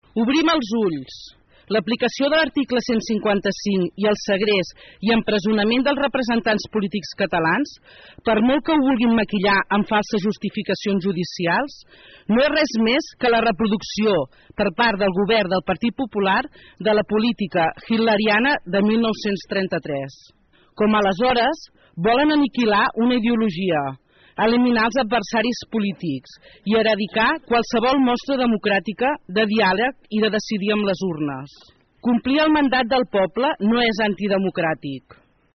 Divendres més d’un centenar de persones es van concentrar a la plaça major per mostrar el seu rebuig a l’empresonament provisional i sense fiança dels 5 diputats independentistes; Jordi Turull, Josep Rull, Carme Forcadell, Dolors Bassa i Raul Romeva per ordre del jutge Pablo Llarena.
Durant la concentració es va llegir un manifest de protesta contra la “repressió” que viu l’independentisme.
La regidora d’ERC, Susanna Pla, va ser l’encarregada de llegir el manifest.